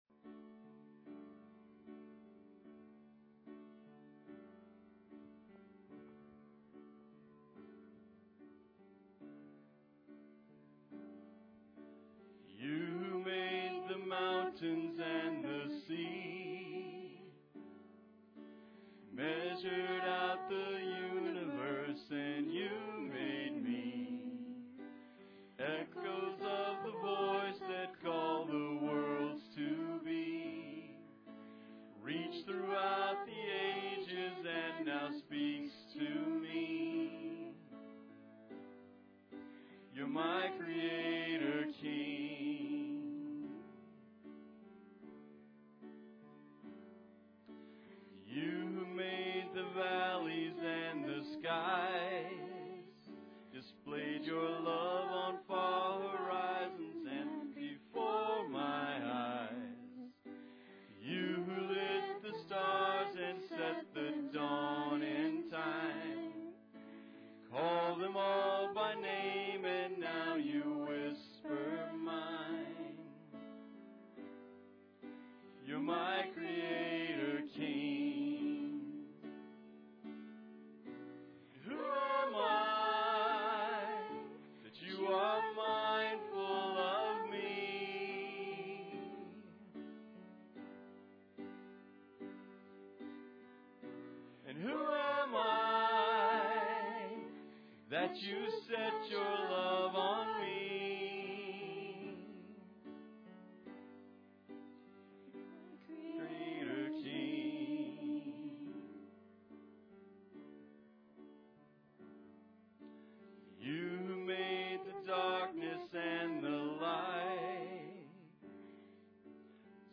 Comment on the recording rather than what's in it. Prayer Passage: Colossians 1:9-12 Service Type: Sunday Service Introduction The determining cause